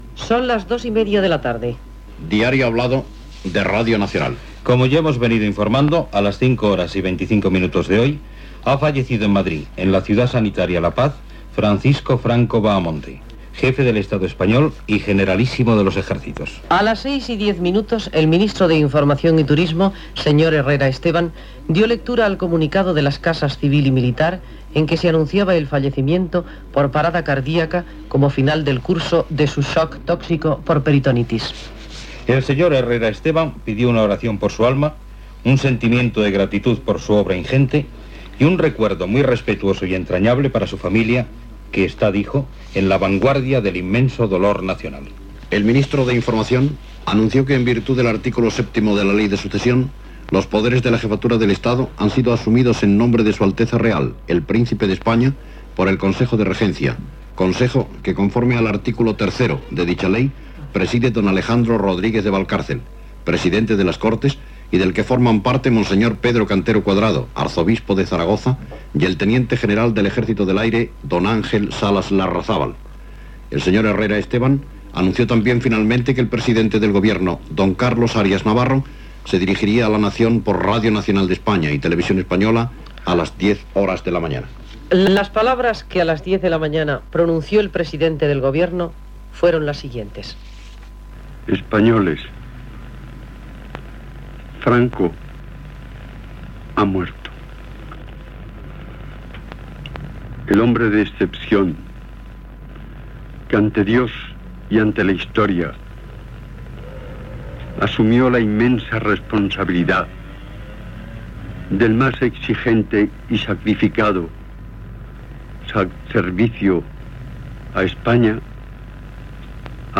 Paraules del president del govern espanyol Arias Navarro. Cròniques des d'Argentina, Veneçuela, Xile, França, Unió Soviètica, Gran Bretanya, Itàlia i Bèlgica.
Gènere radiofònic Informatiu